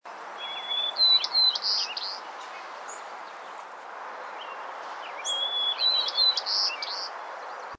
White-crowned Sparrows, Dane County 5/9/2003
White Crowned Sparrow MP3
white-crowned sparrow.mp3